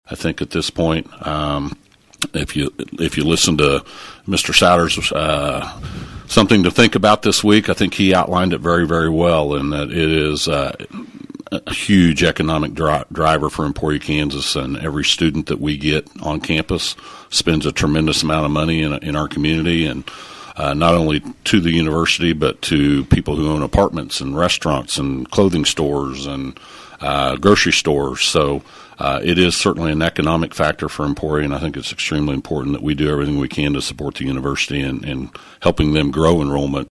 Longbine also spoke at length about plans to bolster funding to higher education, including Emporia State University.